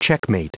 Prononciation du mot checkmate en anglais (fichier audio)
Prononciation du mot : checkmate